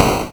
fami_hurt.ogg